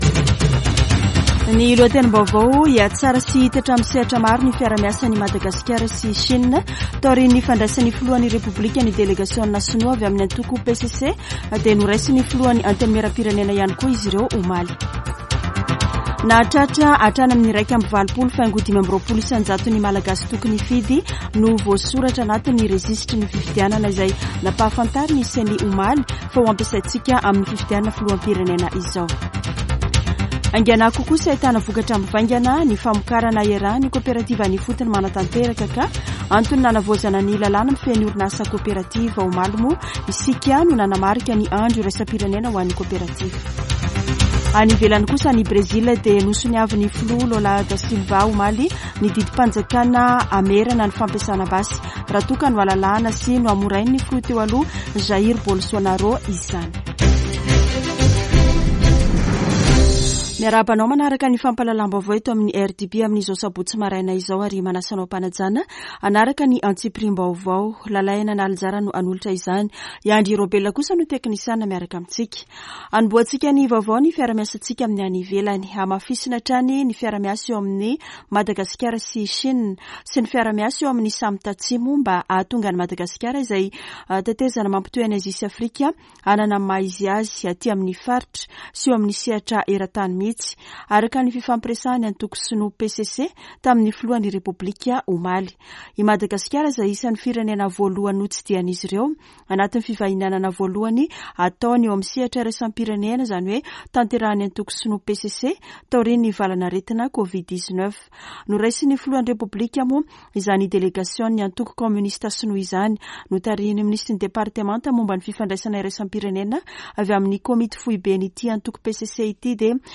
[Vaovao maraina] Sabotsy 22 jolay 2023